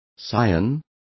Also find out how vastago is pronounced correctly.